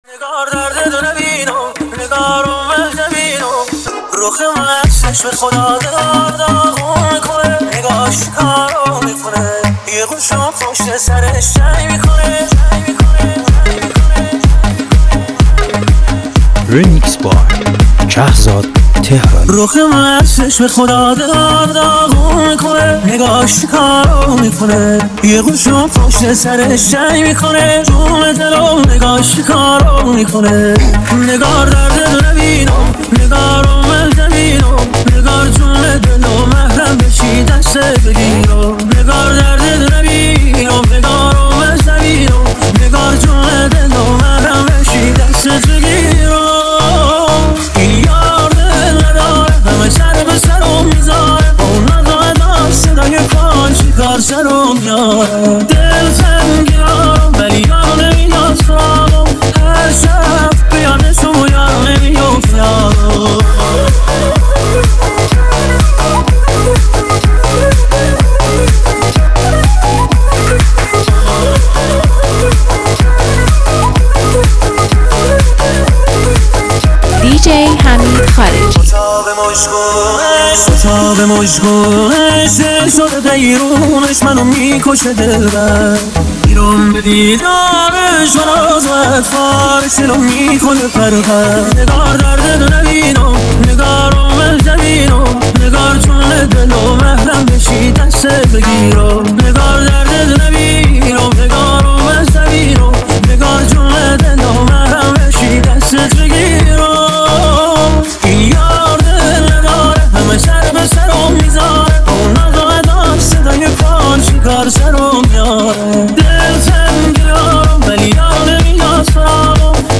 این میکس پرانرژی برای مهمانی‌ها و دورهمی‌ها مناسبه.